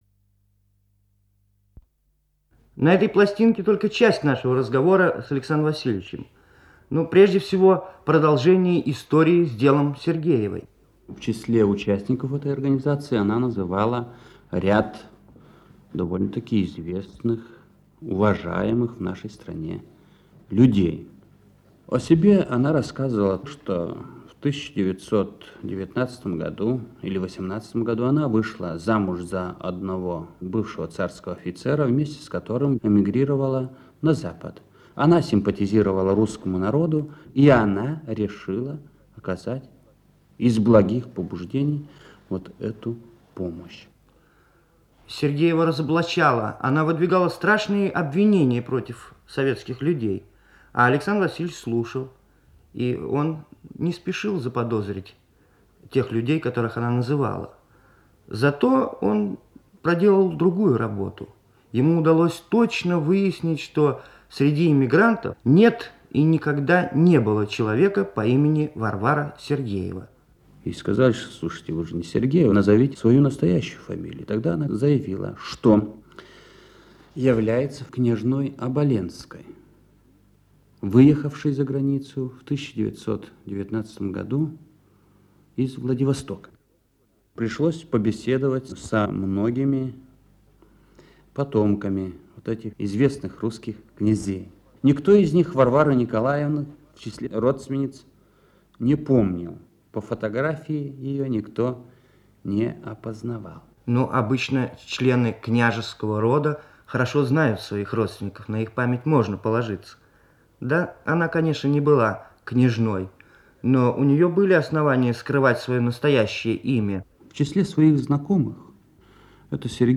В его речи и теперь слышится оттенок северного говорка.